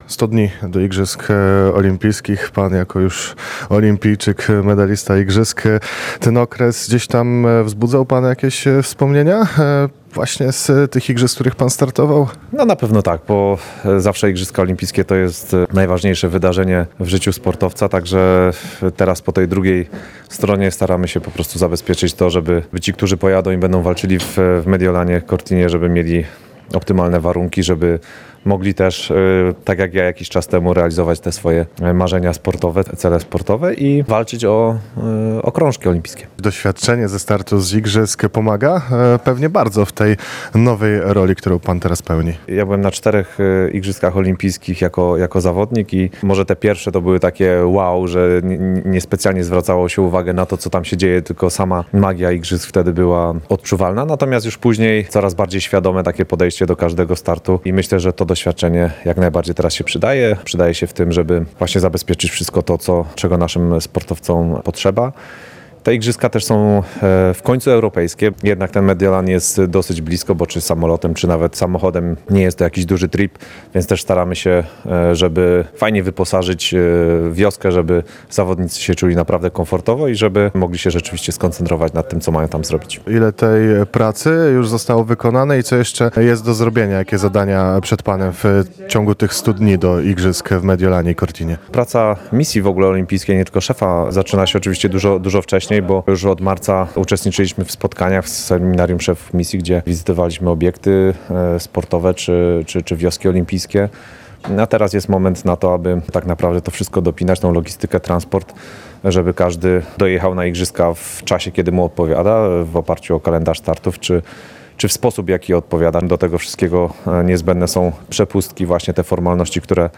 Już mniej niż 100 dni pozostało do Zimowych Igrzysk Olimpijskich w Mediolanie i Cortinie d’Ampezzo. Z tej okazji w Lublinie odbyła się specjalna konferencja z udziałem prezesa PKOL-u Radosława Piesiewicza oraz szefa Polskiej Misji Olimpijskiej Konrada Niedźwiedzkiego.